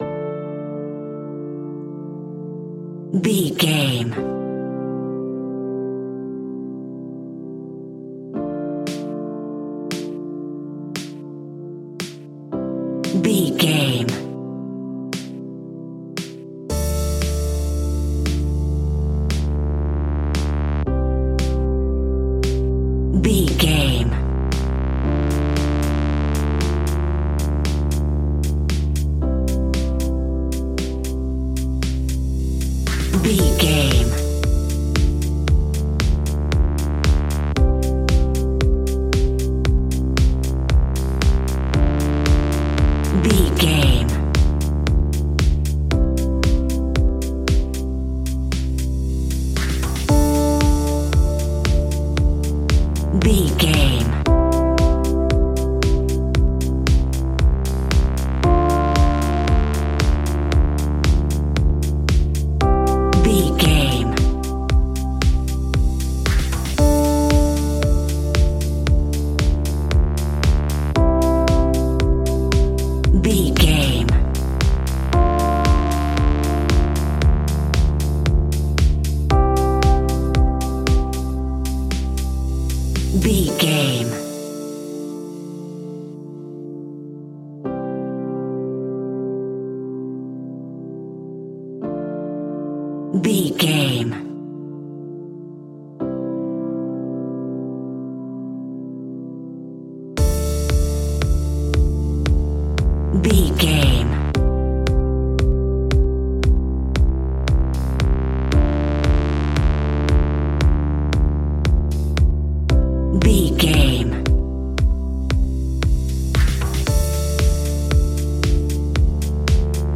Modern Electronic Dance Pop Alt.
Aeolian/Minor
groovy
uplifting
futuristic
driving
energetic
synthesiser
drum machine
electric piano
synthwave
synth leads
synth bass